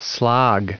Prononciation du mot slog en anglais (fichier audio)
Prononciation du mot : slog